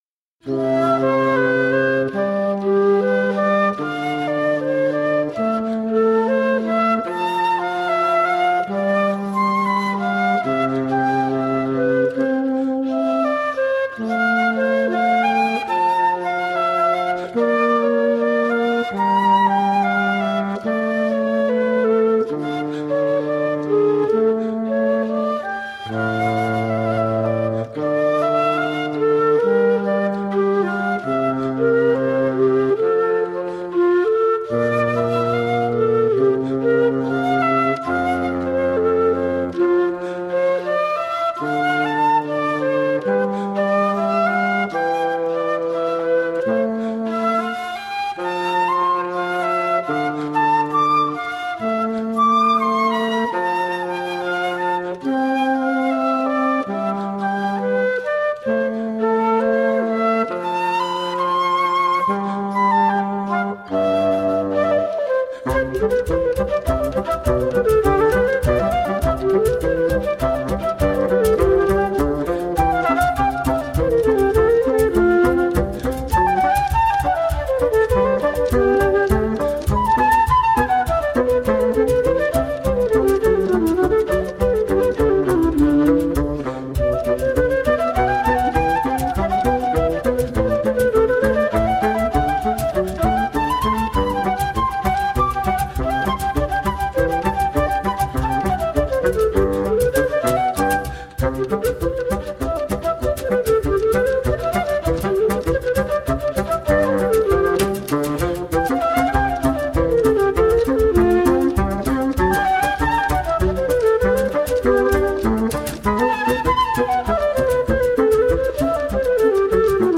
Brazilian contemporary and traditional flute.
with flute, acoustic guitar, fagot and percussion